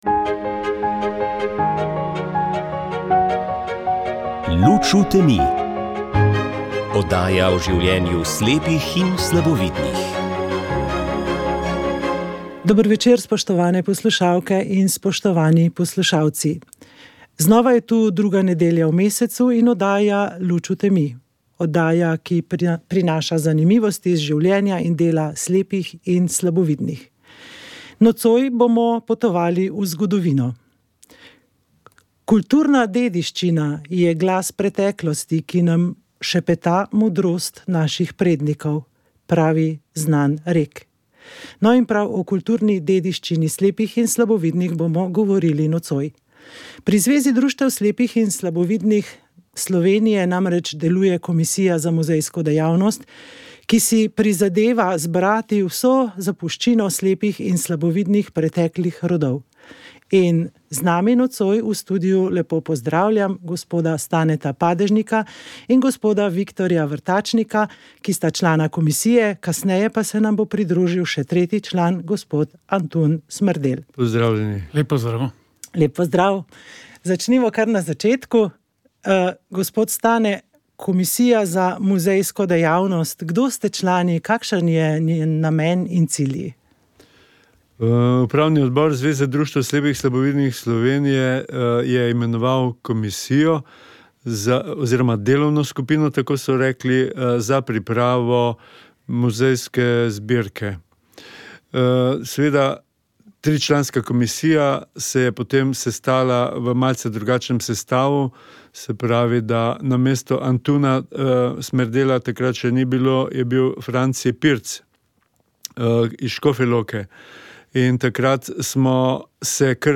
Zadnje letošnje druženje v Doživetjih narave smo posvetili dobri planinski glasbi in triglavskemu ledeniku, ki se poslavlja tako kot staro leto.